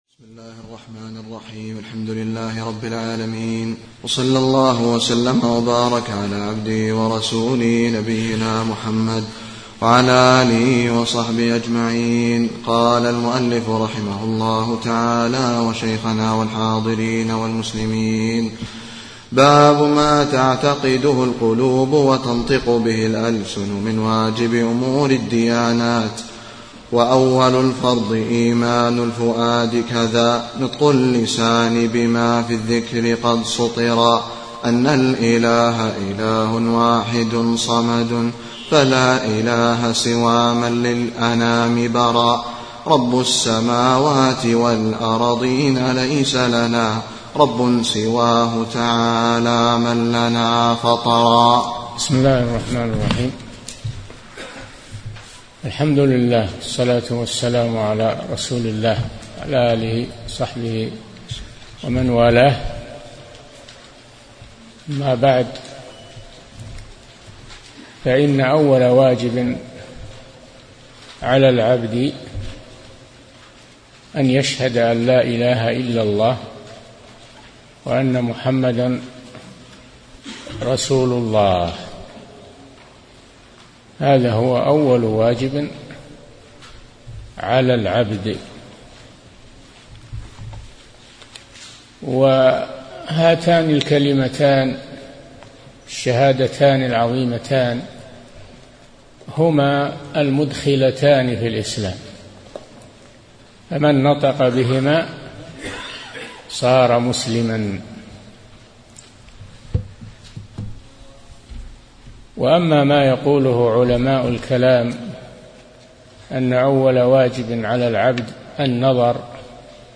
أرشيف الإسلام - ~ أرشيف صوتي لدروس وخطب ومحاضرات الشيخ صالح بن فوزان الفوزان